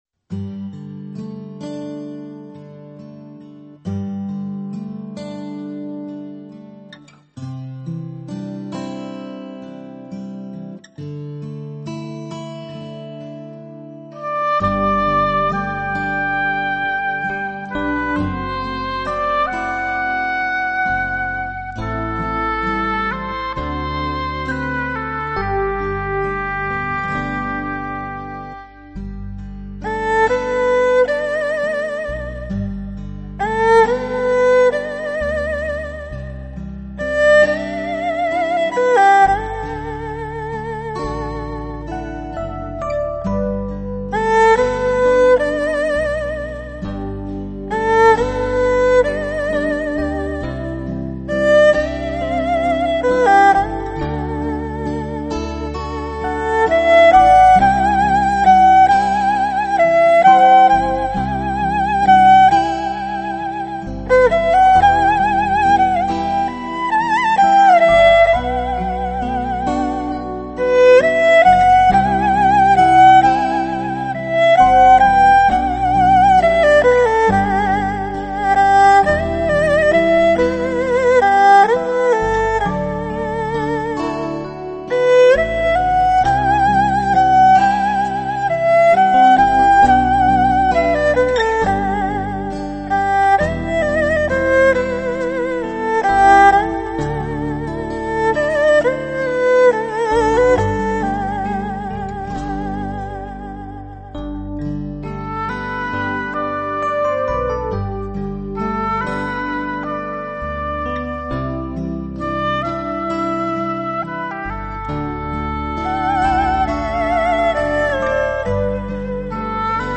器乐：二胡
所属类型：民乐